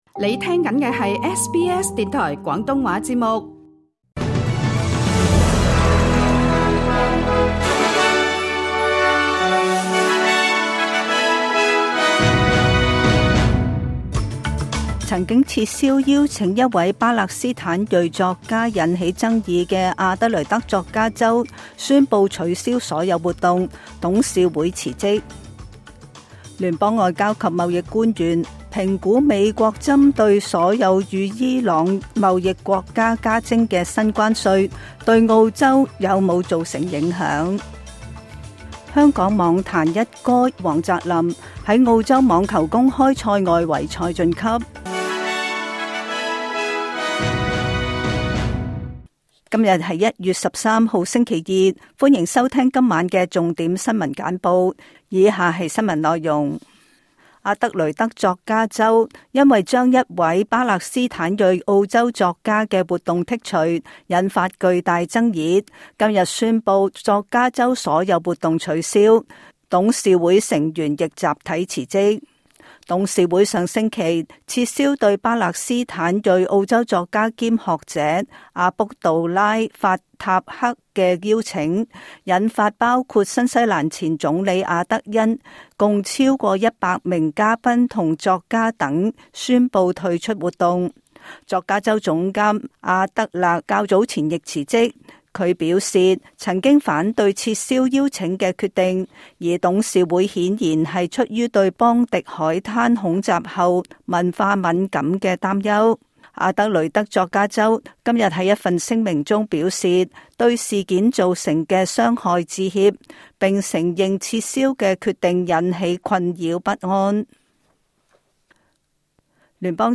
請收聽本台為大家準備的每日重點新聞簡報。